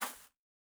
Bare Step Grass Medium A.wav